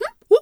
zebra_whinny_08.wav